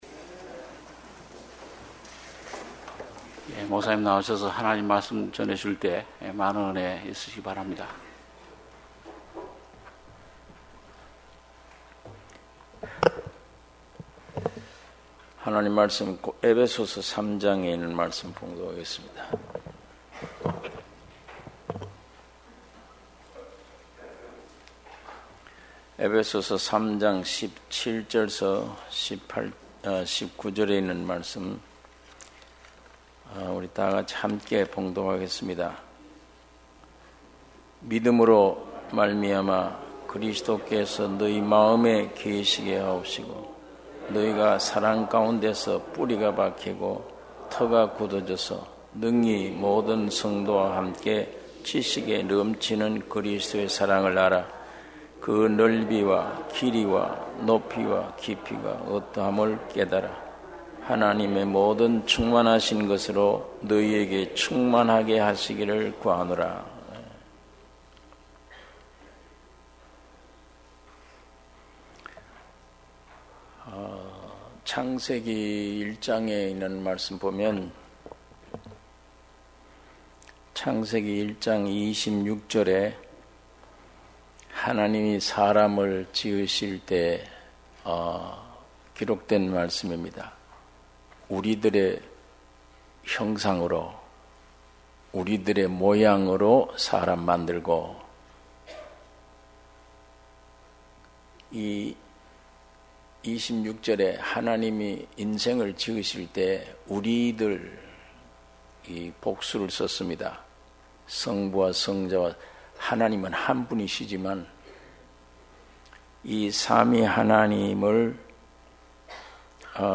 주일예배 - 에베소서 3장 17-19절